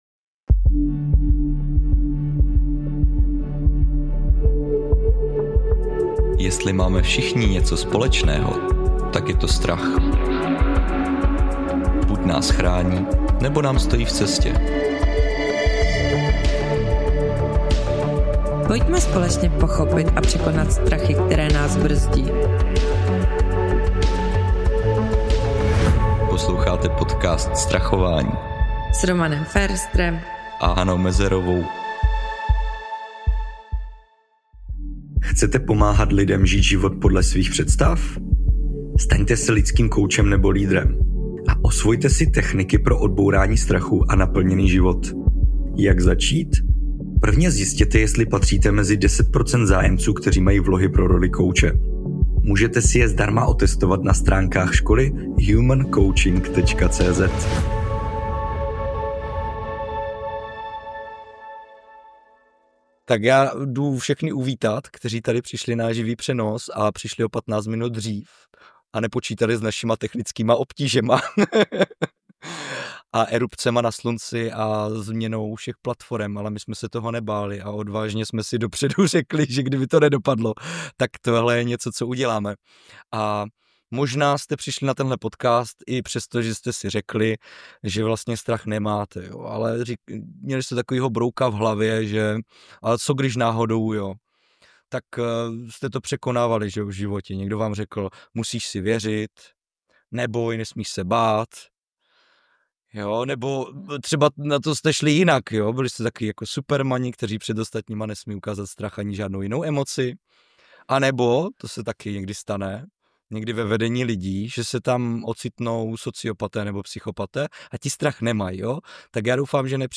V tomhle záznamu živého vysílání